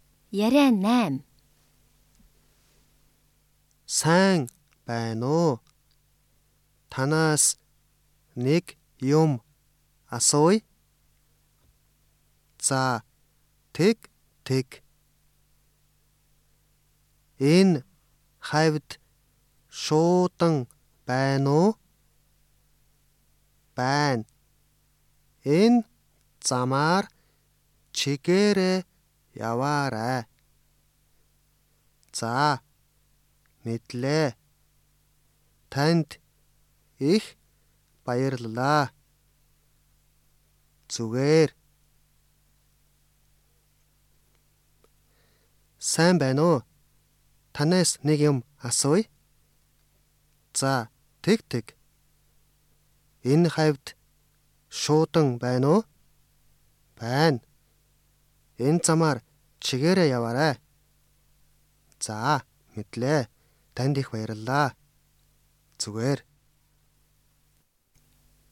会話のスピ ー ドがゆっくりと通常の２パタ
サンプル音声１